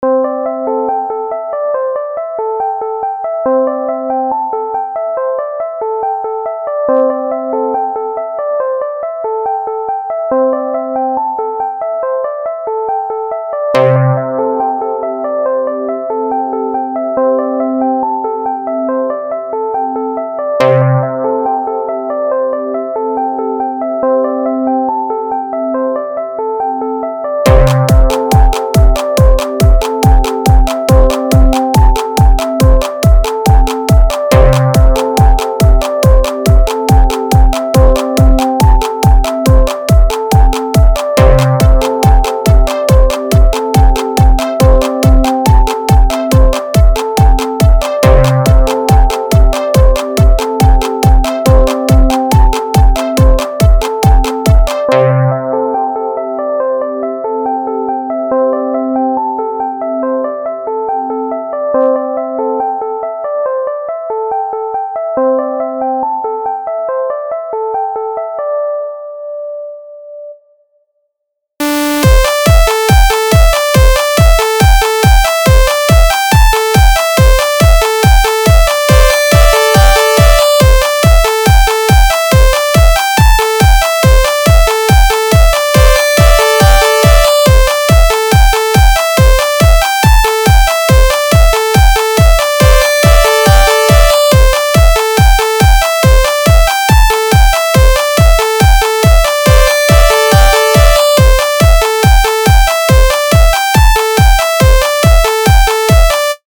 • 曲风：House？